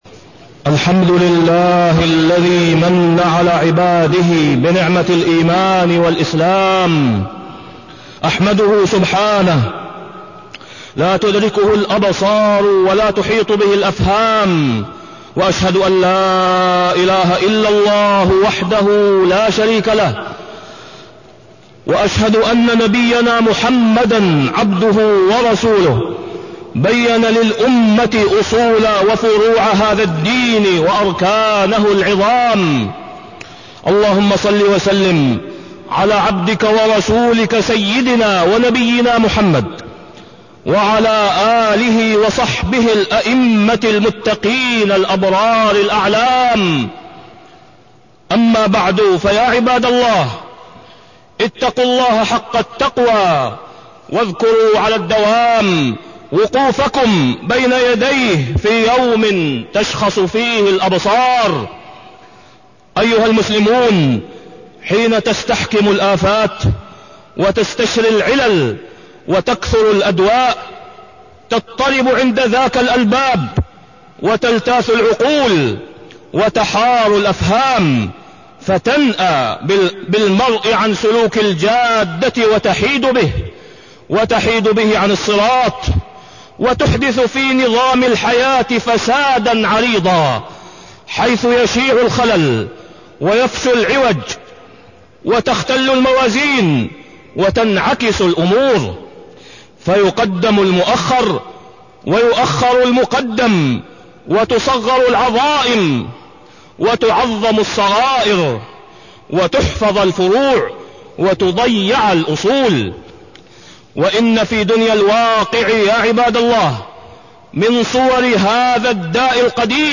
تاريخ النشر ٧ ذو القعدة ١٤٢٣ هـ المكان: المسجد الحرام الشيخ: فضيلة الشيخ د. أسامة بن عبدالله خياط فضيلة الشيخ د. أسامة بن عبدالله خياط الشرك بالله